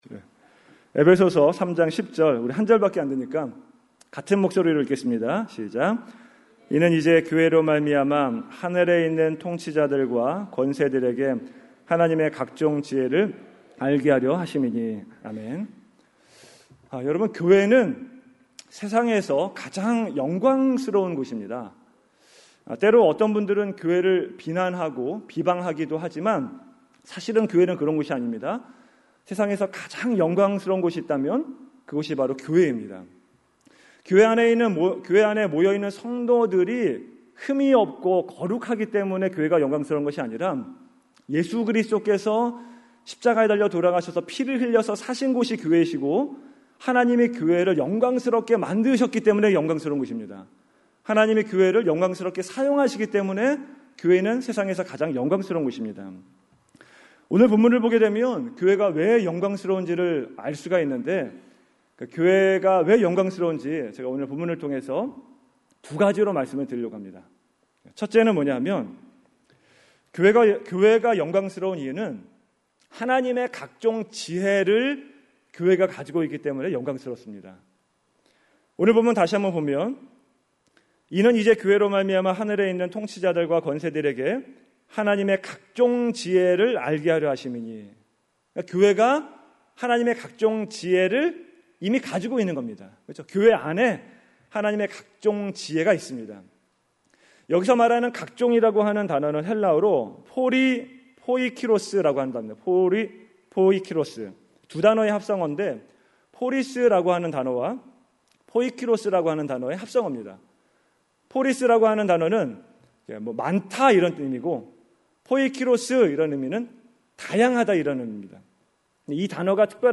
2017 금요설교 – Page 4 – Maranatha Vision Church